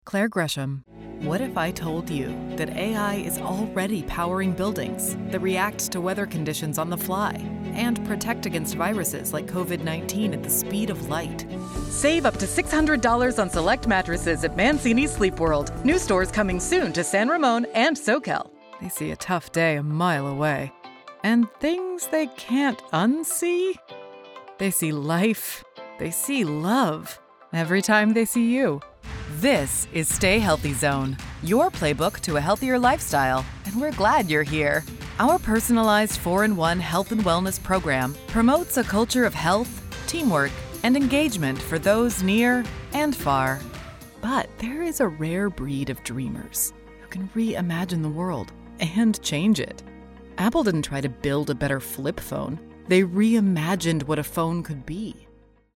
Home Studio Reel
General American, US Southern (various dialects), British RP
Middle Aged